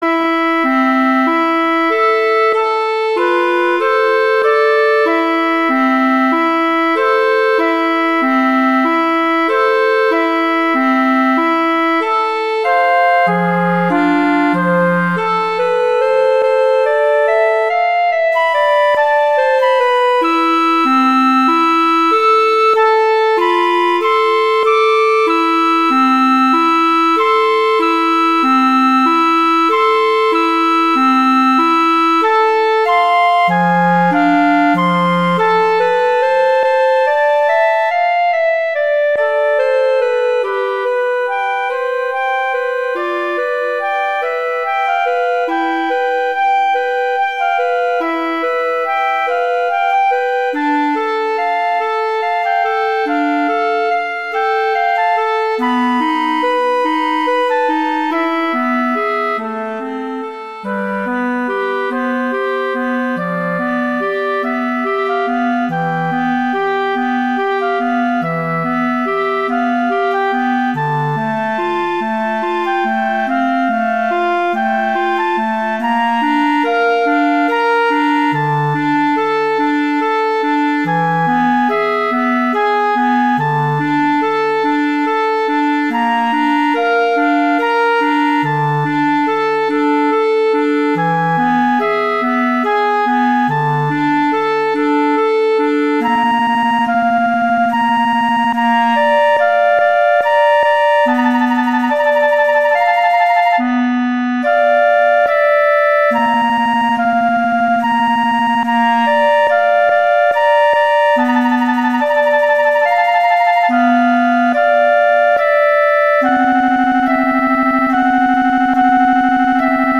Instrumentation: flute & clarinet
arrangements for flute and clarinet
classical, french